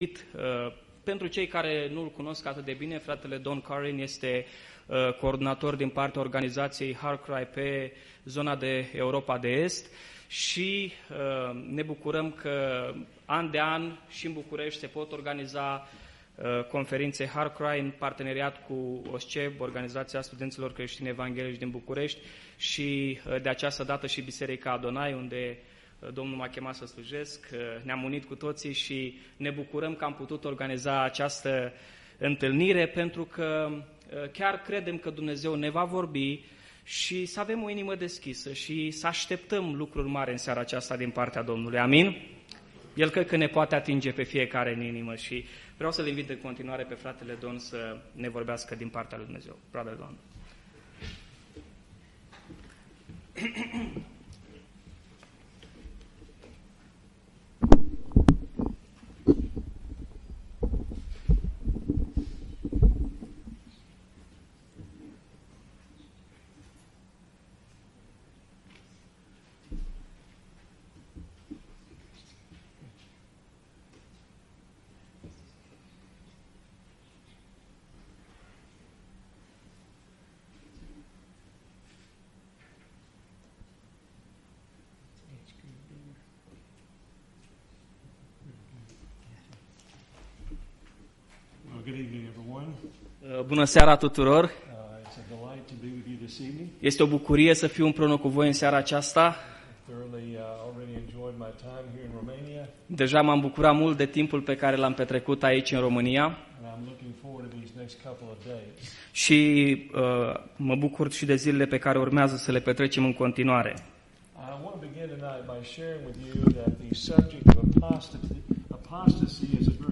Predici Complete